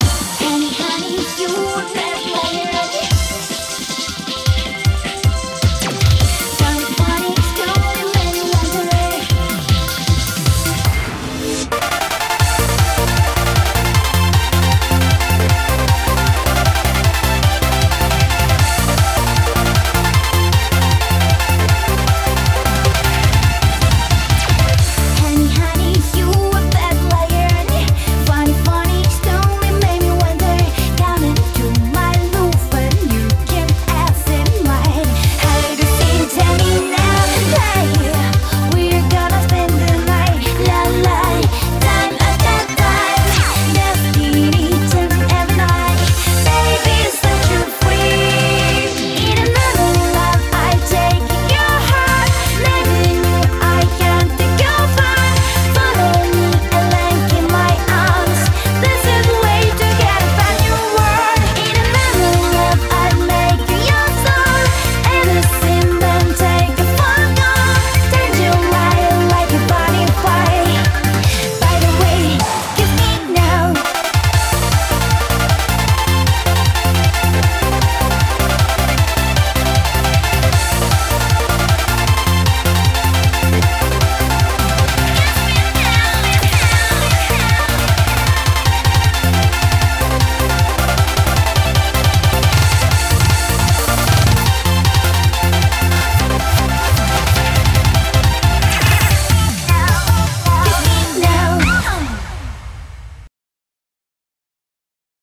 BPM155